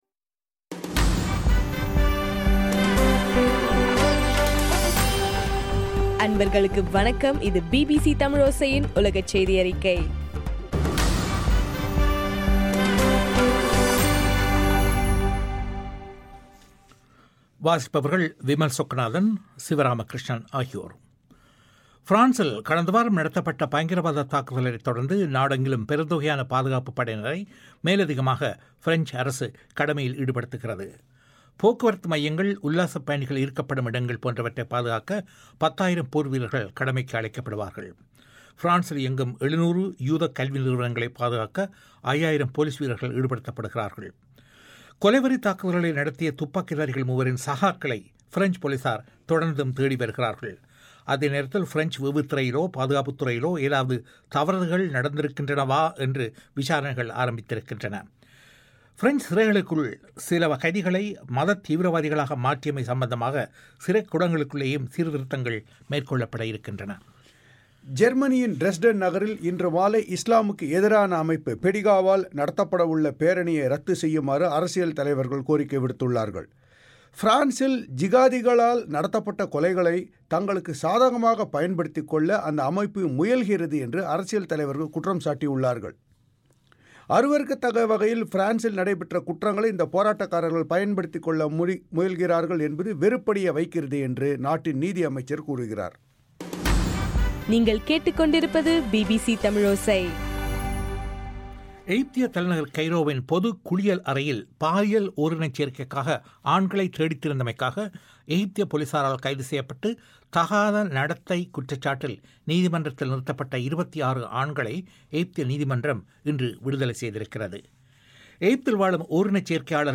ஜனவரி 12 பிபிசி தமிழோசை உலகச் செய்தி அறிக்கை